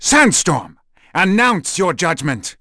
Zafir-Vox_Skill3.wav